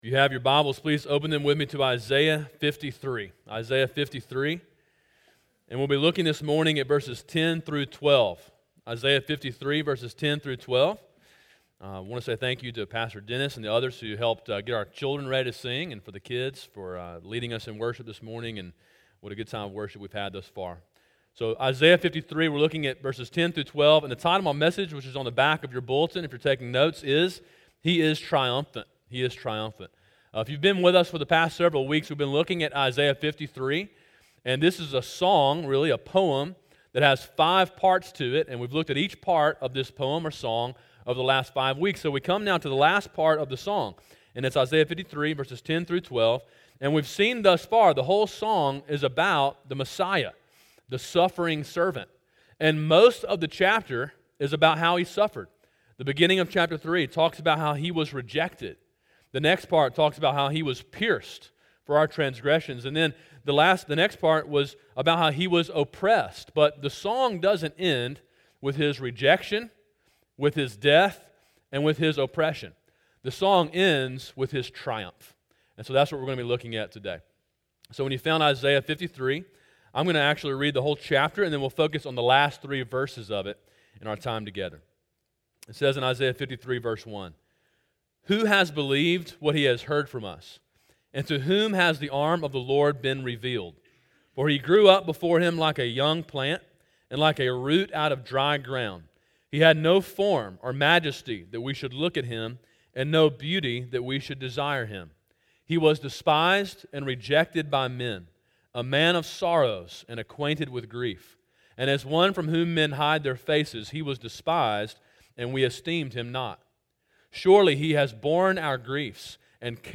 Sermon: “He Is Triumphant” (Isaiah 53:10-12)